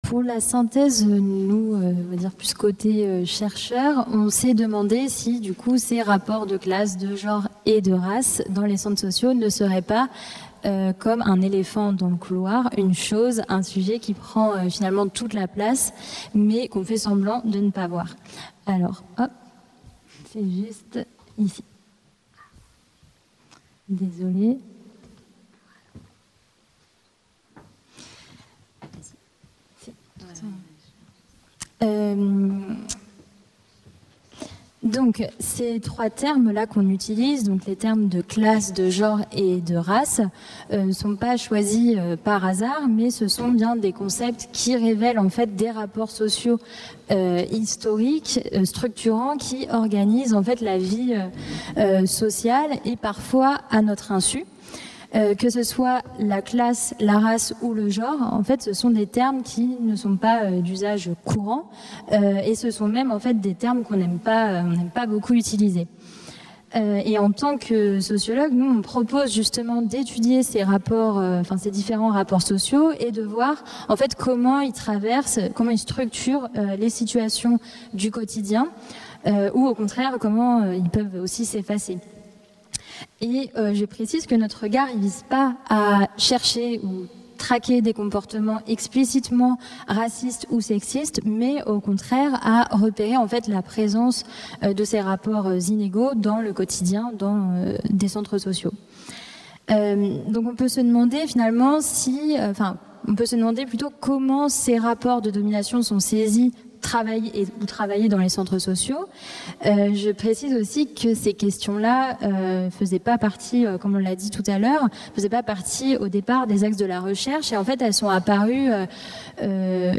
Table ronde 4